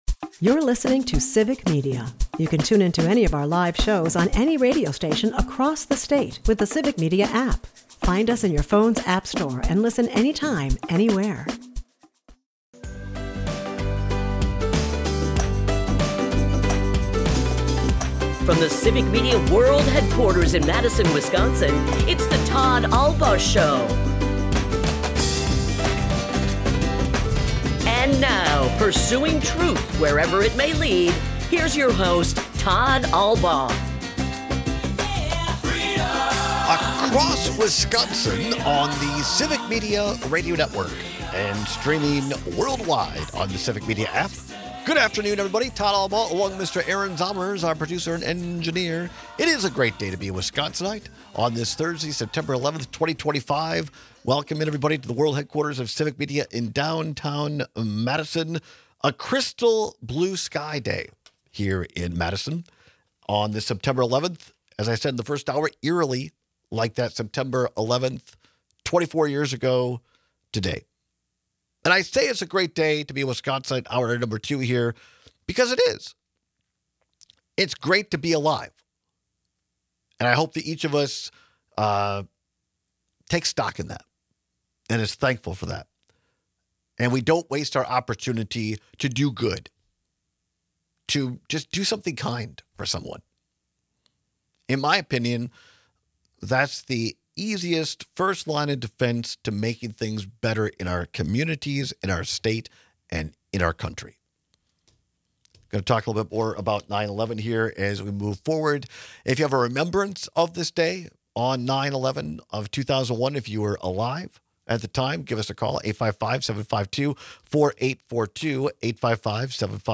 In today’s second hour, we continue taking calls to talk about 9/11 remembrances and reactions to the Charlie Kirk assassination.